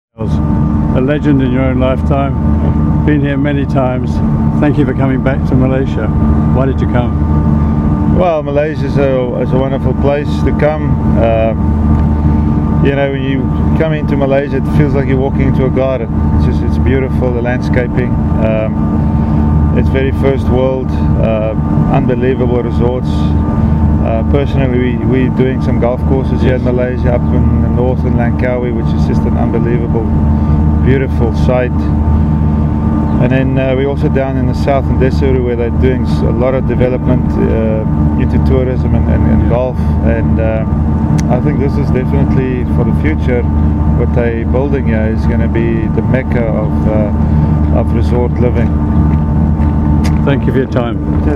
MGTA interviews Ernie Els